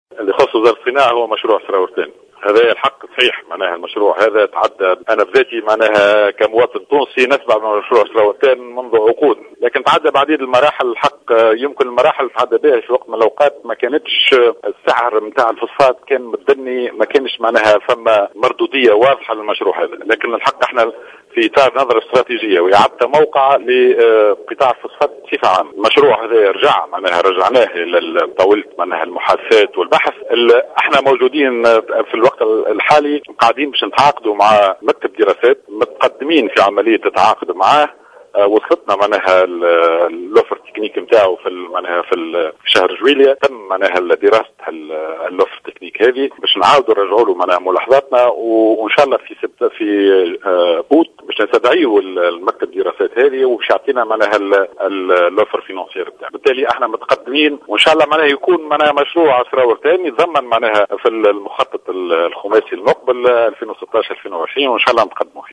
أكد وزير الصناعة زكريا حمد في تصريح
خلال زيارته لولاية الكاف